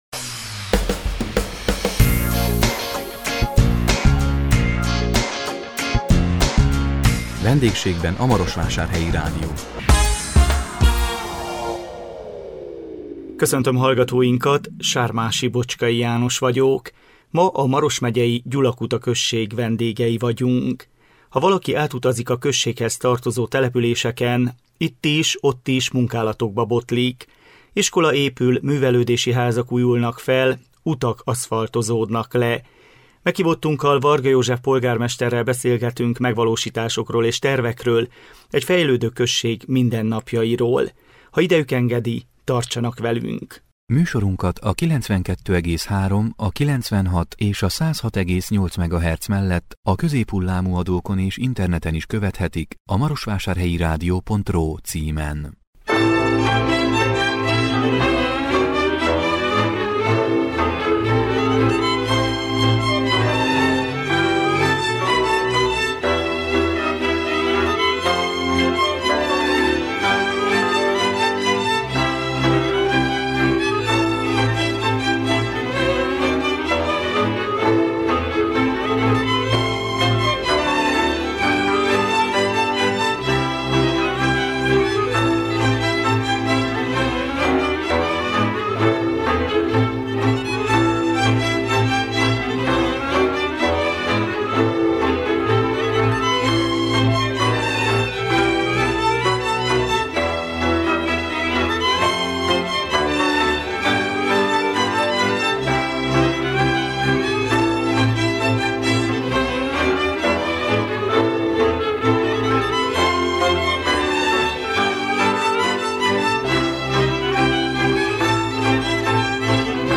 Iskola épül, művelődési házak újulnak fel, utak aszfaltozódnak le. Meghívottunkkal, Varga József polgármesterrel beszélgettünk megvalósításokról és tervekről, egy fejlődő község mindennapjairól.